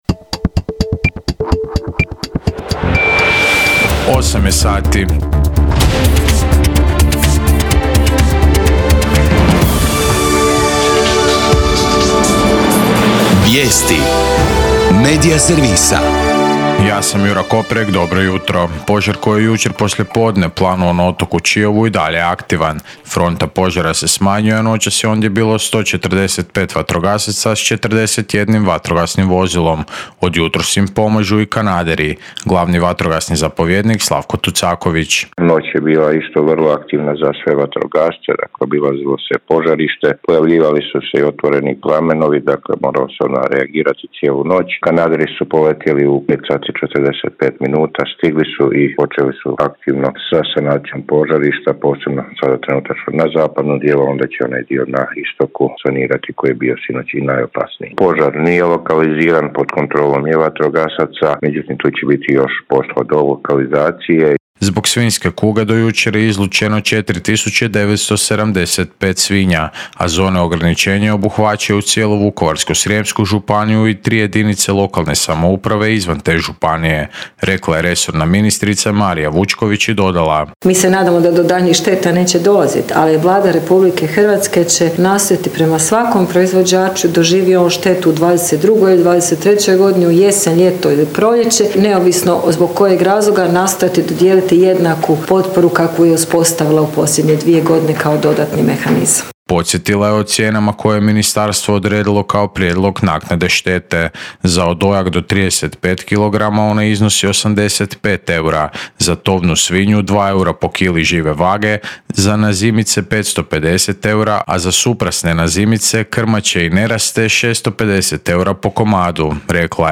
VIJESTI U 8